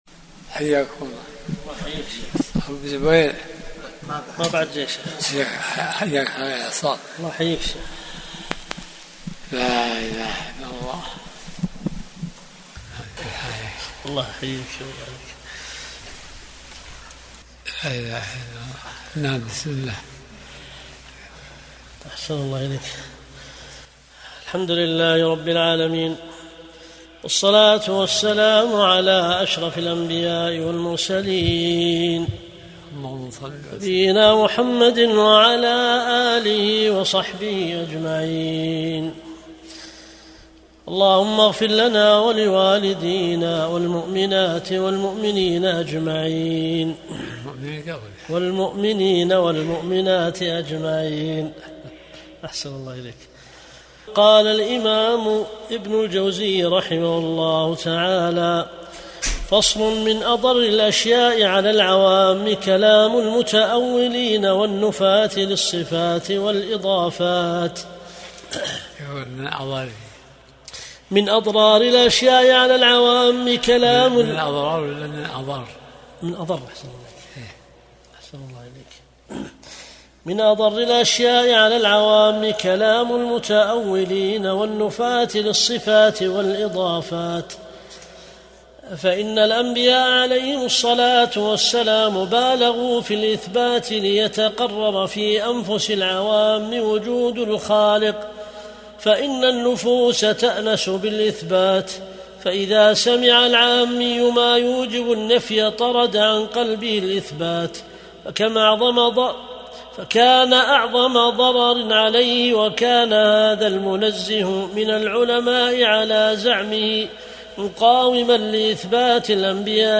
درس الأحد 56